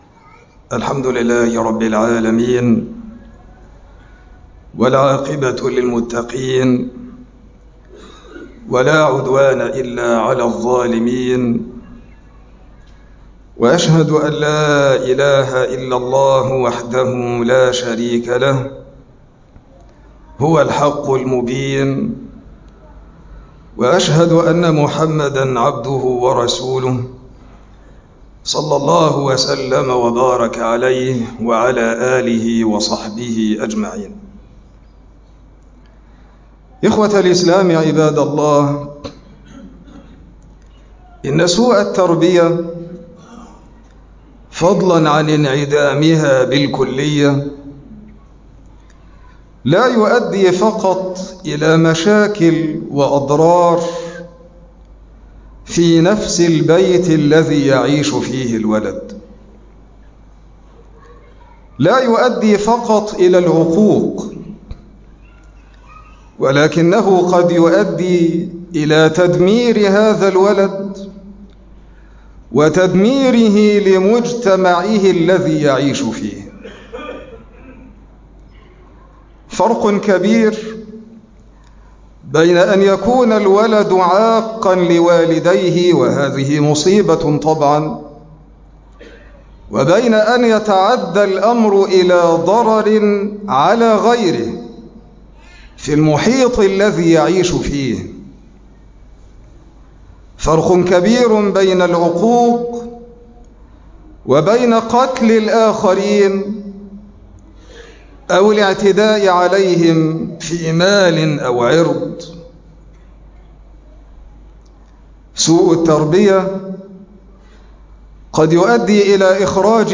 مقطع من خطبة الجمعة: الإسلام والعنف الأسري